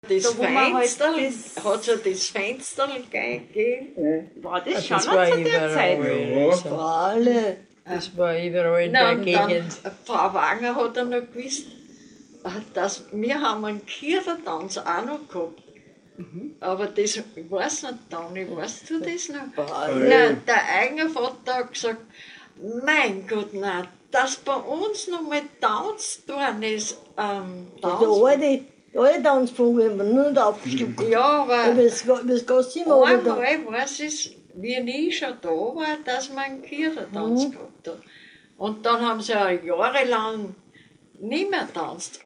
Fensterln Kirtagstanz